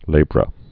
(lābrə)